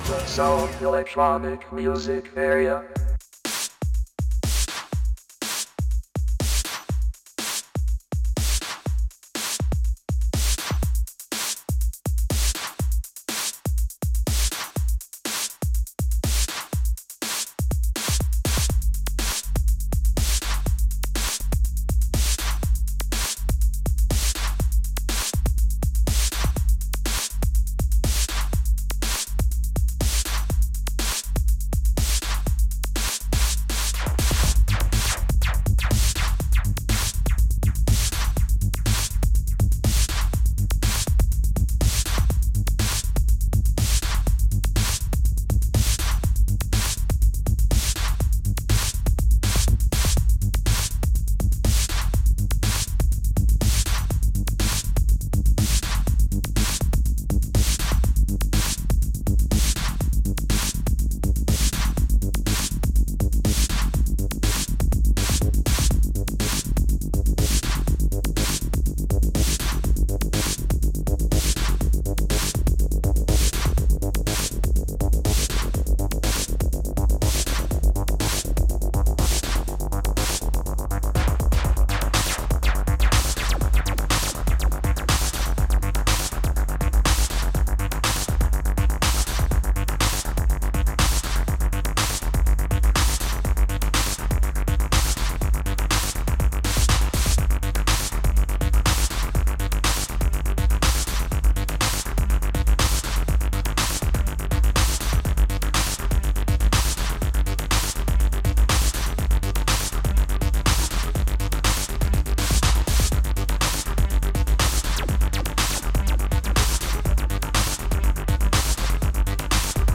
WITH FULL AUDIO HQ REMASTERED
DJSET ONAIR LIVE and 1st hour with Promo&Upcoming Releases
DJset